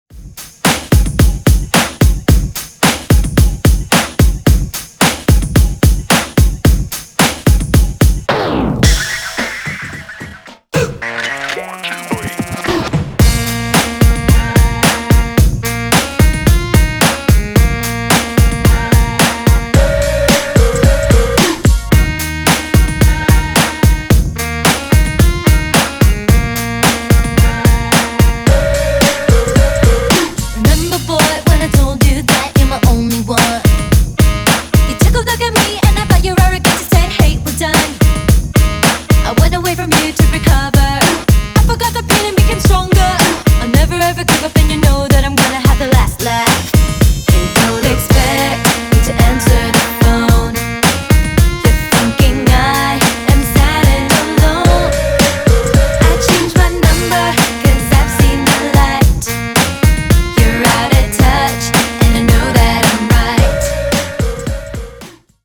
Genres: 90's , RE-DRUM , REGGAE
Clean BPM: 95 Time